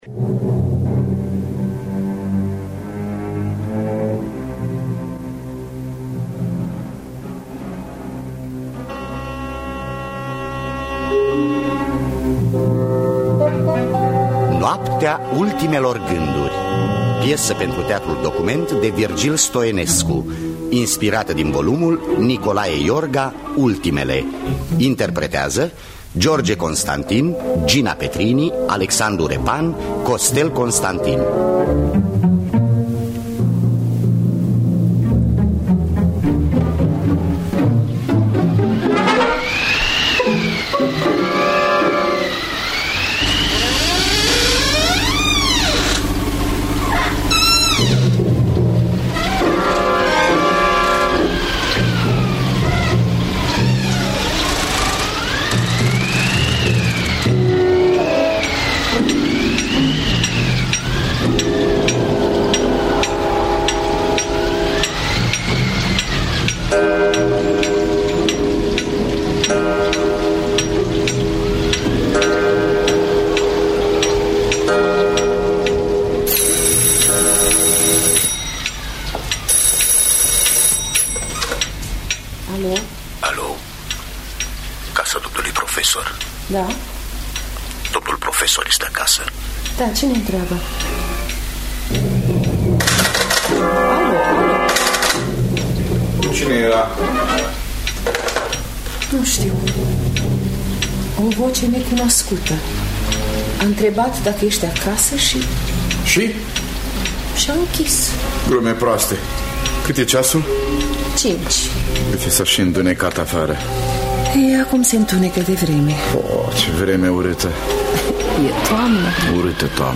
Teatru Radiofonic Online
În distribuţie: George Constantin, Gina Petrini, Alexandru Repan, Costel Constantin.